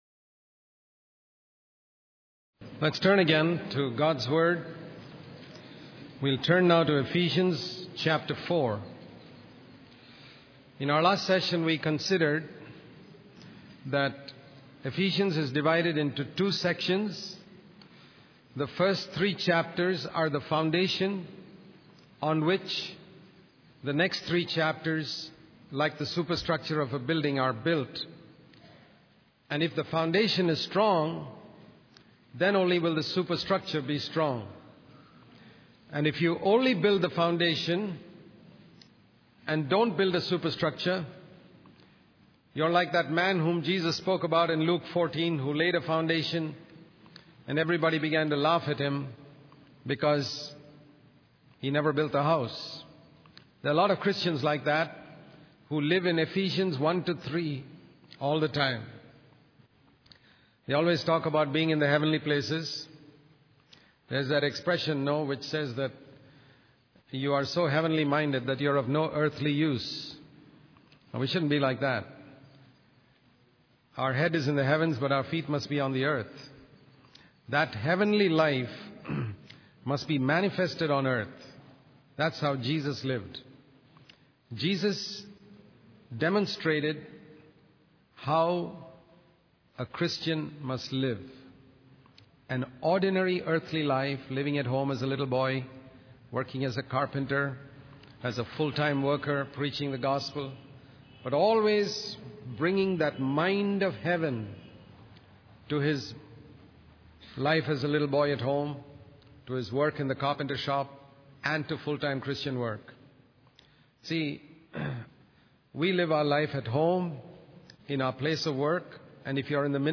In this sermon, the speaker emphasizes the importance of Christians walking in a manner worthy of their calling. The first key aspect mentioned is humility, which is seen as essential for proper praise and worship. The sermon then delves into three areas of relationships: husbands and wives, children and parents, and masters and servants.